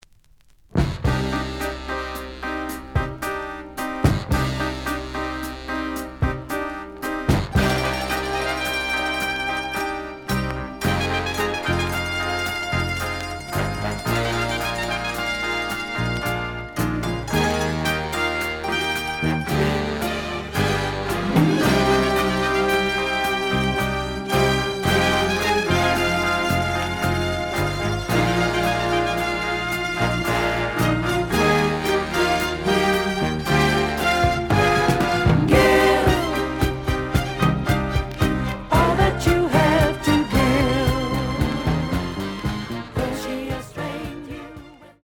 The audio sample is recorded from the actual item.
●Genre: Soul, 70's Soul
Slight affect sound.